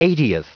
Prononciation du mot eightieth en anglais (fichier audio)
Prononciation du mot : eightieth